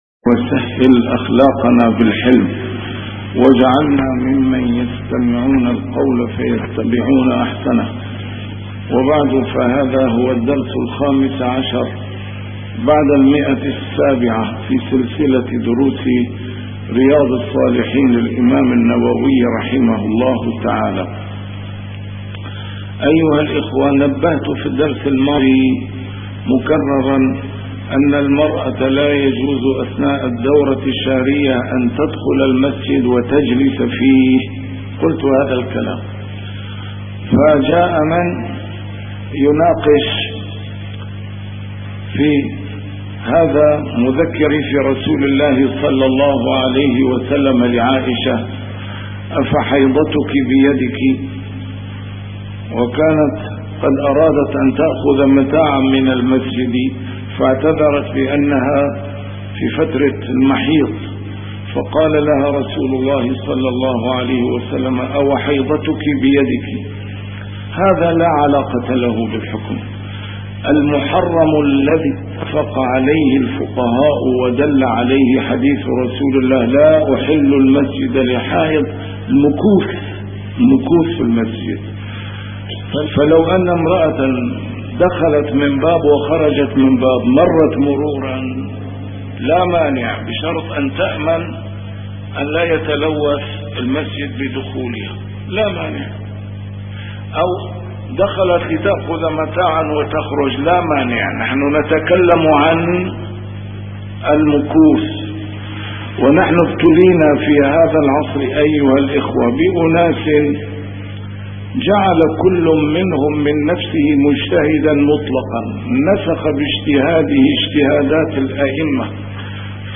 A MARTYR SCHOLAR: IMAM MUHAMMAD SAEED RAMADAN AL-BOUTI - الدروس العلمية - شرح كتاب رياض الصالحين - 715- شرح رياض الصالحين: ما يقرأ في صلاة الجنازة